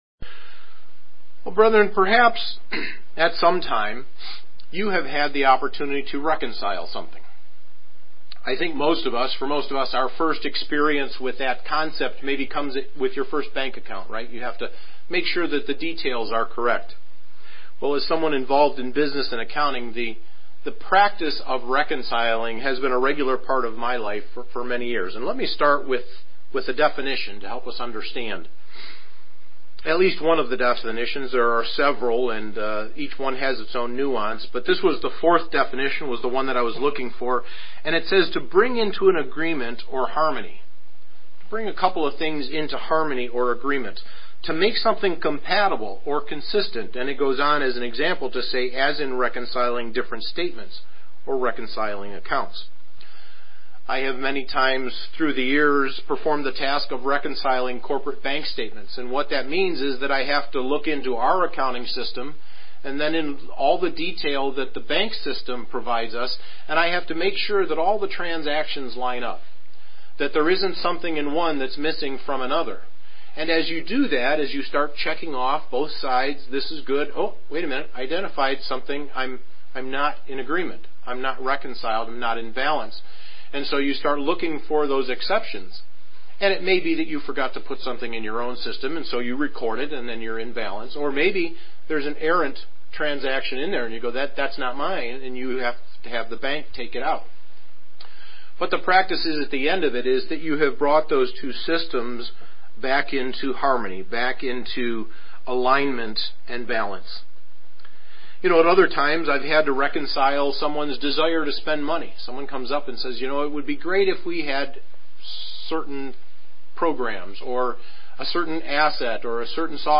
Sermons
Given in Anchorage, AK Soldotna, AK